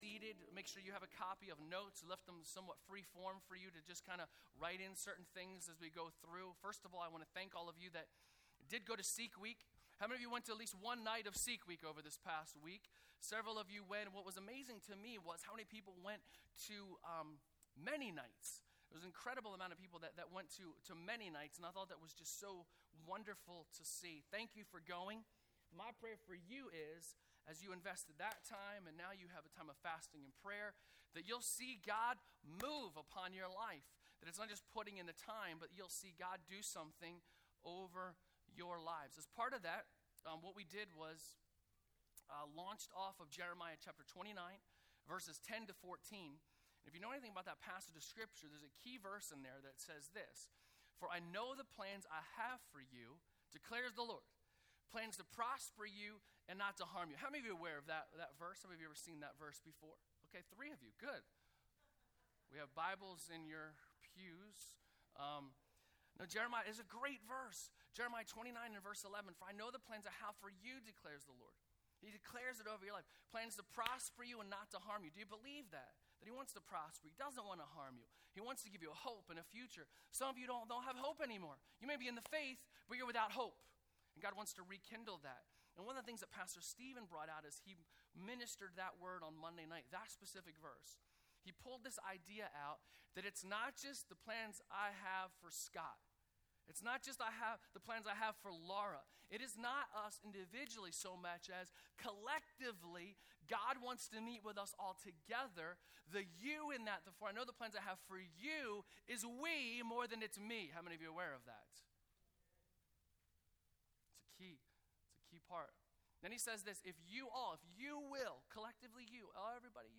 Sermons | Bethany Church